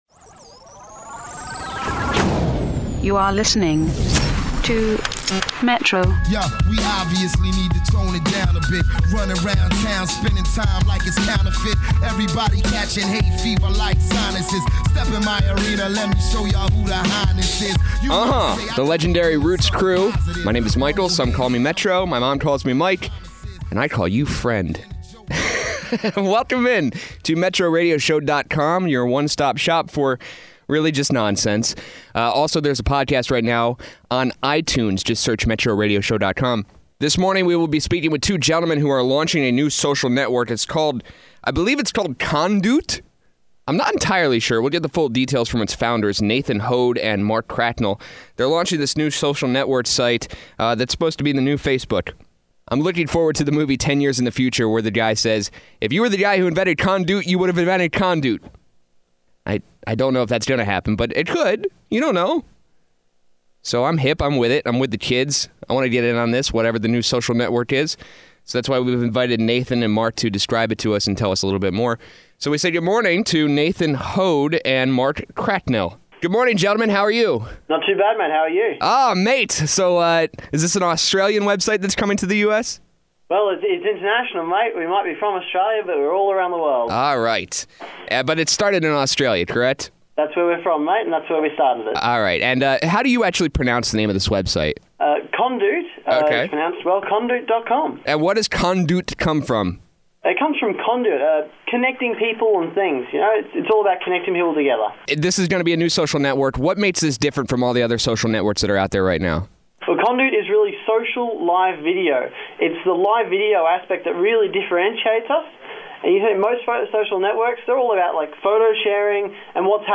kondoot-interview-web.mp3